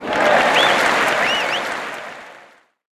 audienceCheer.ogg